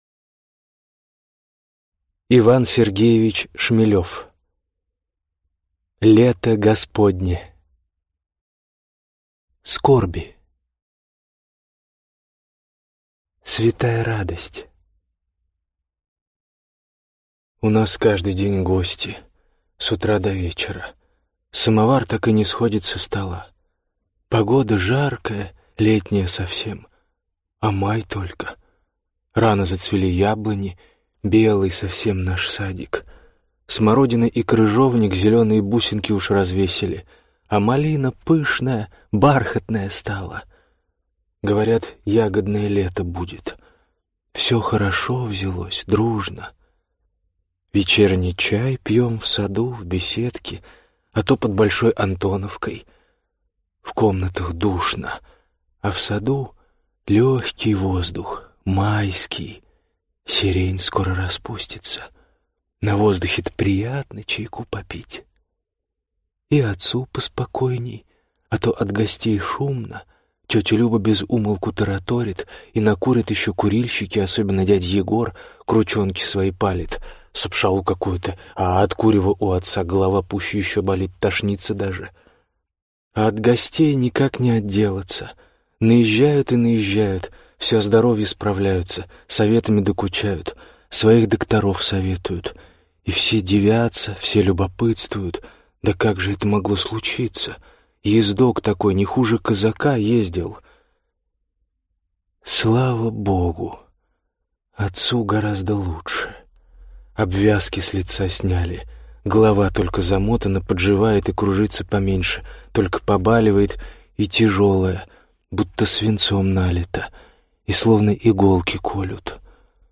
Аудиокнига Лето Господне. Скорби | Библиотека аудиокниг